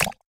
sfx_handgun_fire.mp3